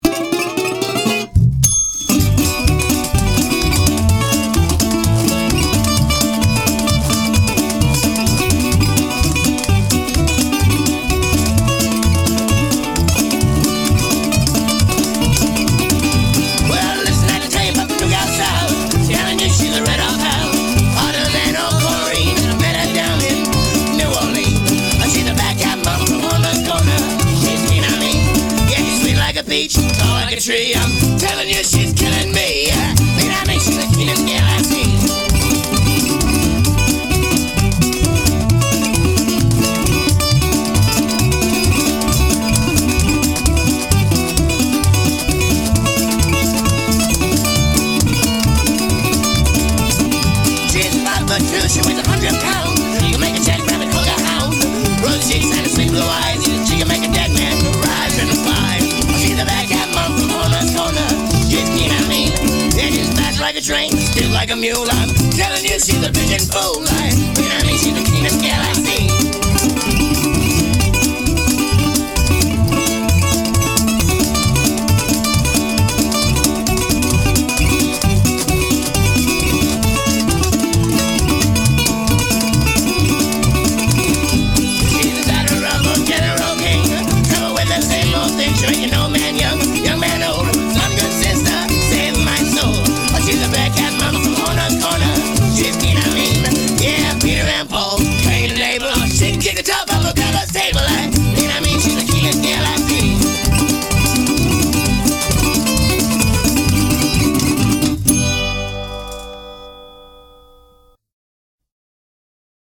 A ragtime gallimaufry from Eugene, Oregon U$A